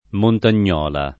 montagnola
montagnola [ montan’n’ 0 la ]